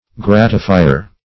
Gratifier \Grat"i*fi"er\, n. One who gratifies or pleases.